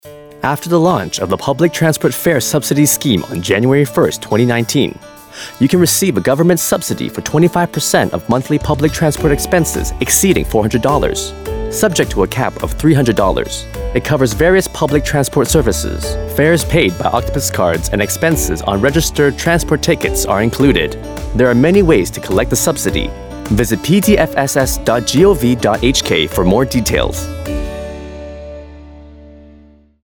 Radio Announcements in the Public Interest